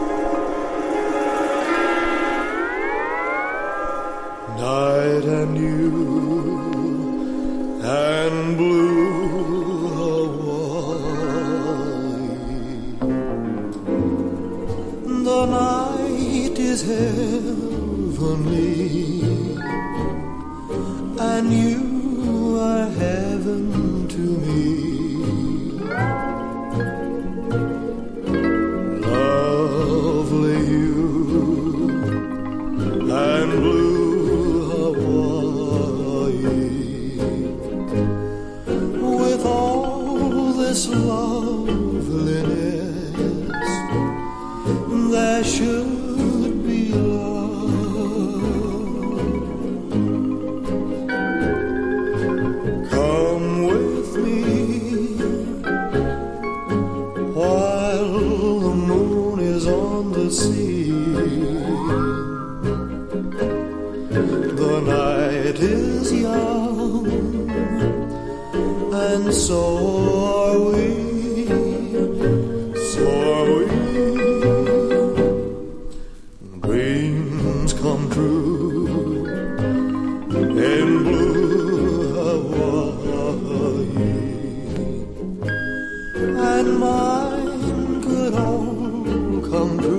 1. 60'S ROCK >